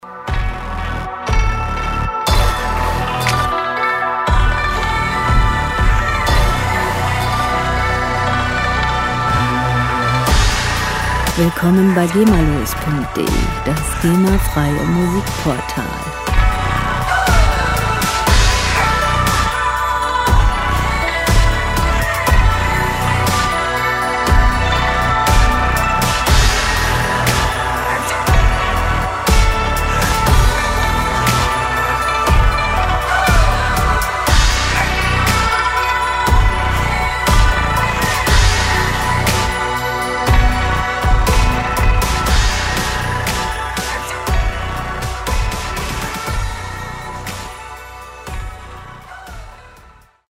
• Asia Dubstep